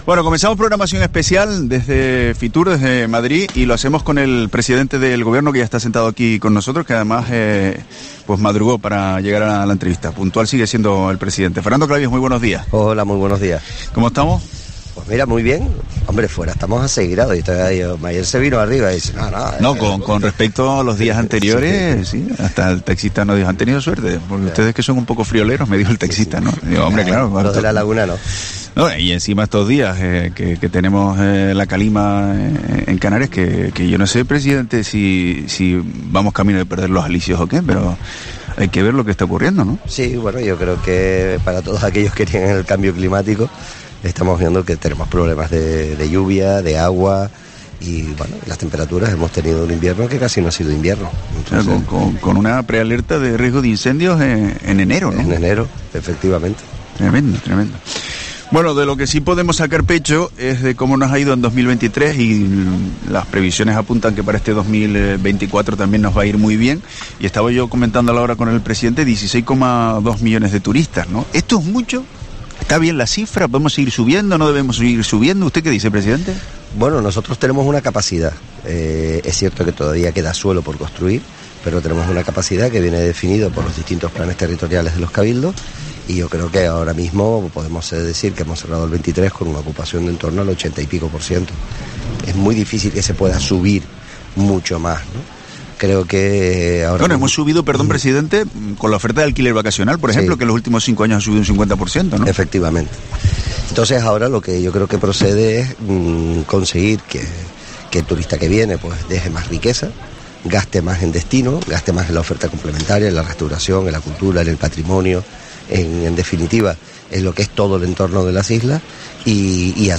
Entrevista al presidente de Canarias, Fernando Clavijo, en FITUR 2024